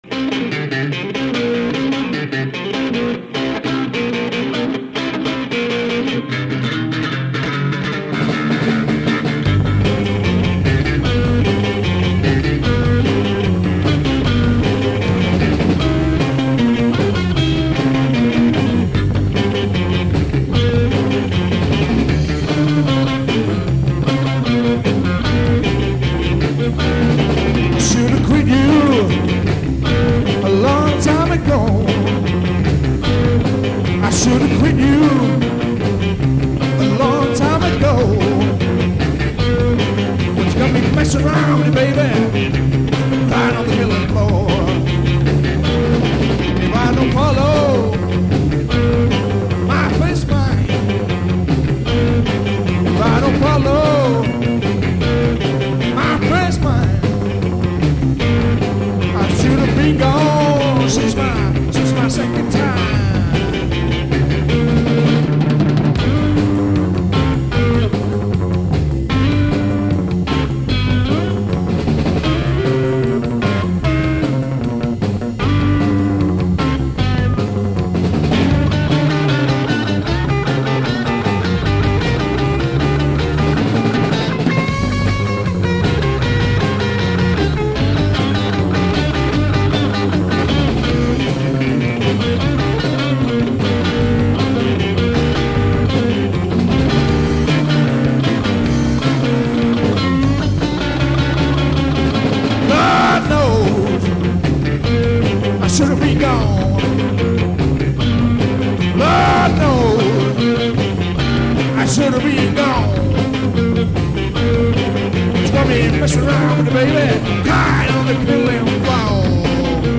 An energised trad blues number.